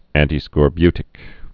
(ăntē-skôr-bytĭk, ăntī-)